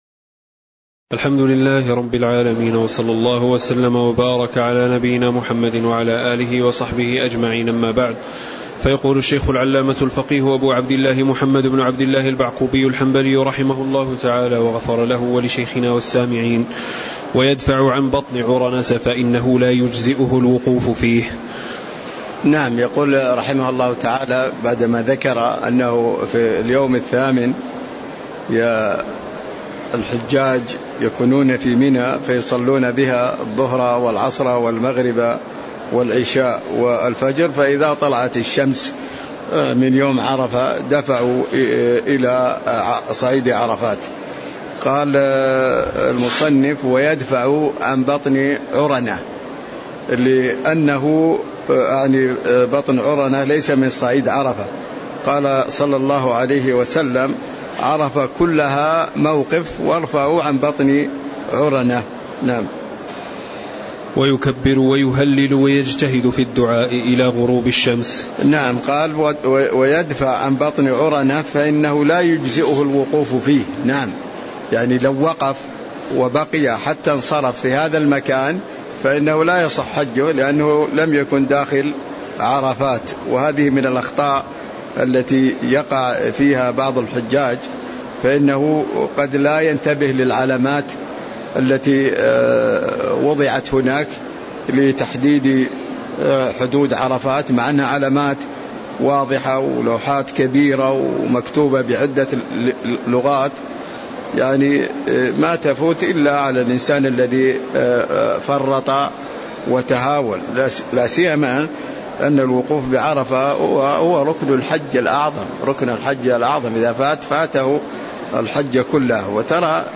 تاريخ النشر ٣ ذو الحجة ١٤٤٤ هـ المكان: المسجد النبوي الشيخ